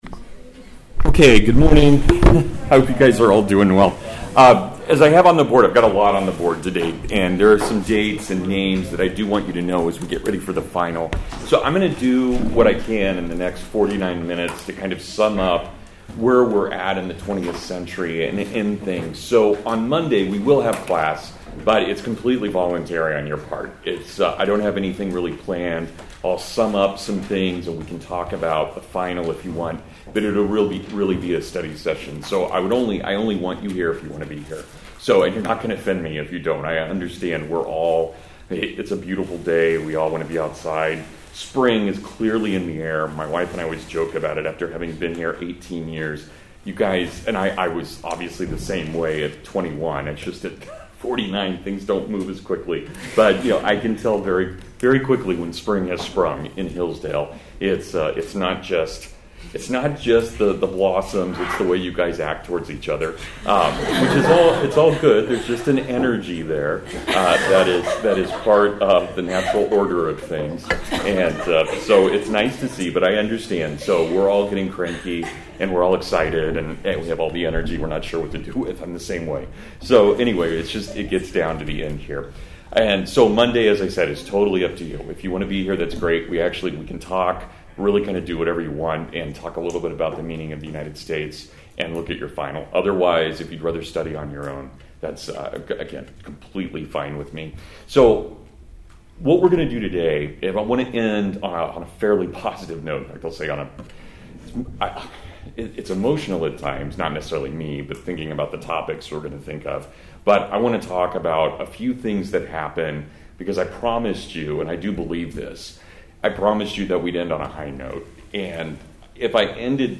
The Immorality of CONTAINMENT (Full Lecture)